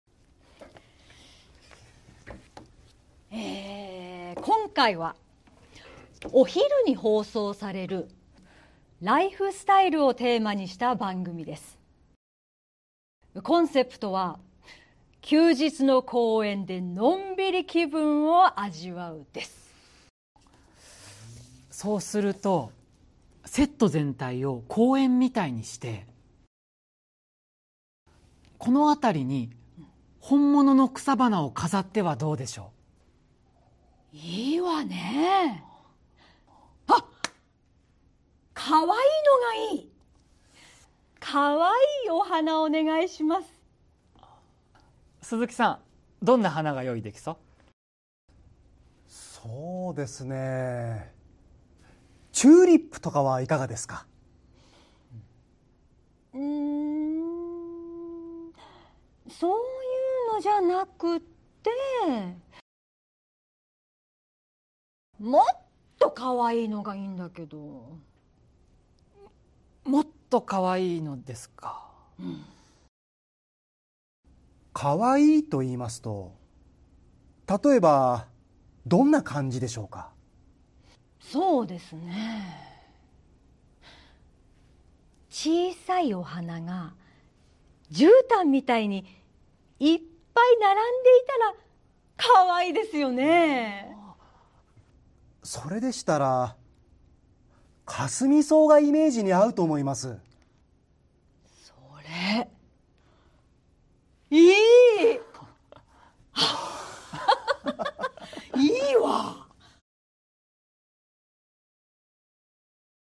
A buyer from a supermarket calls you, saying they want to place an order.
Conversation Transcript